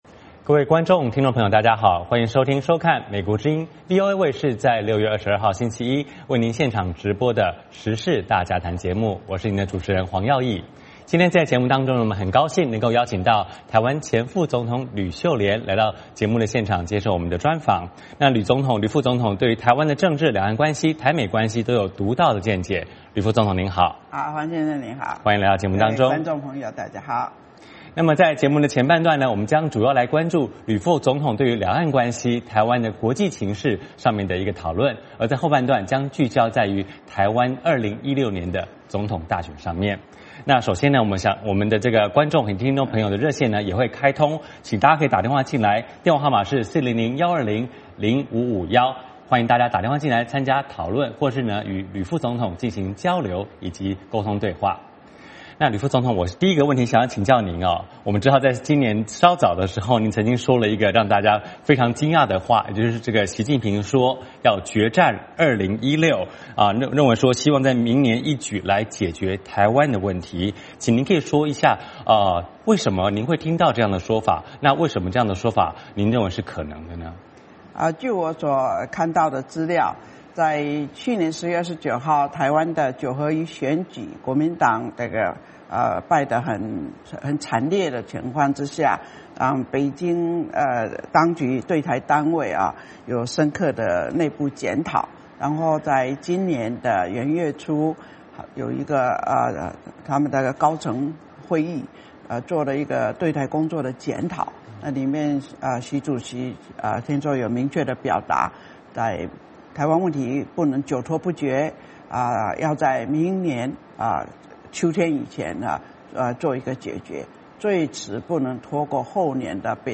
时事大家谈：专访台湾前副总统吕秀莲:两岸关系与台湾国际地位
今天的《时事大家谈》请到台湾前副总统吕秀莲接受美国之音的专访，并且也将与各位观众朋友交流互动。吕副总统对於台湾政治、两岸关系、台美关系等，都有独到见解，节目的前半段主要请吕副总统谈谈她对於两岸关系、台美关系的看法。